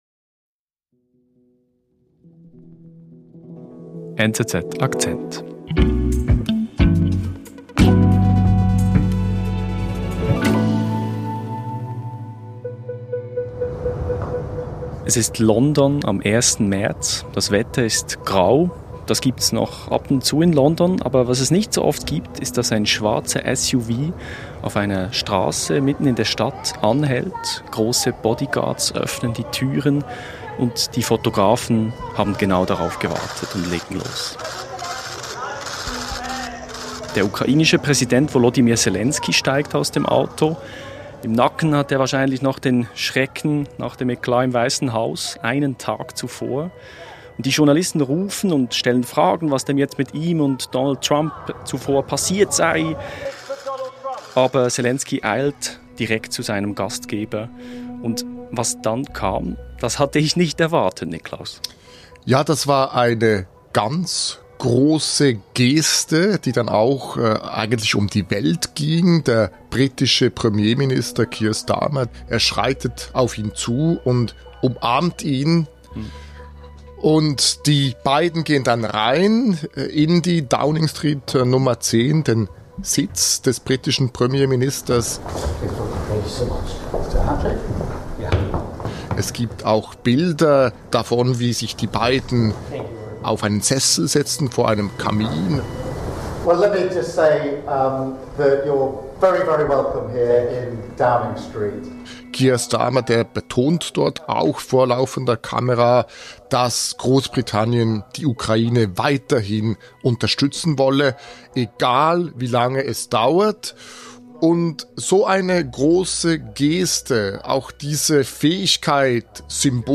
Täglich erzählen NZZ-Korrespondentinnen und Redaktoren, was sie bewegt: Geschichten aus der ganzen Welt inklusive fundierter Analyse aus dem Hause NZZ, in rund 15 Minuten erzählt. Das ist «NZZ Akzent» – täglich ein Stück Welt.